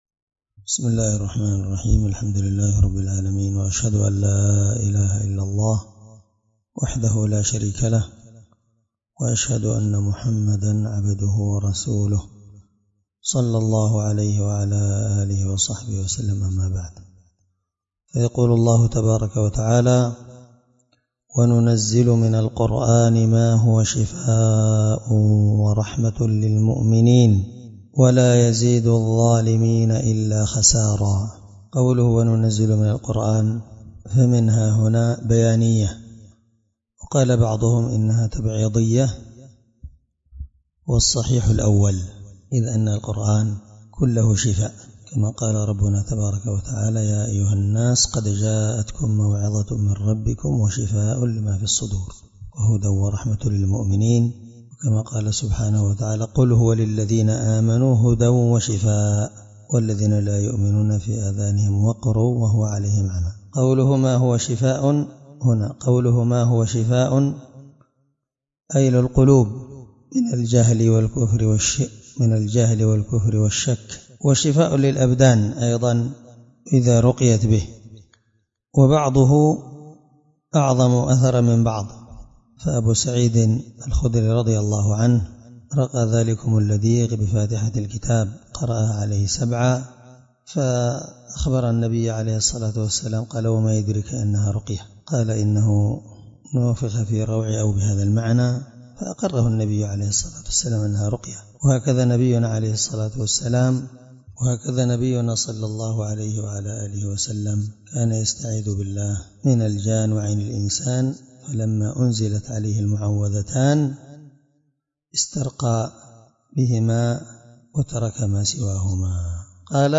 الدرس25 تفسير آية (82) من سورة الإسراء